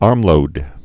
(ärmlōd)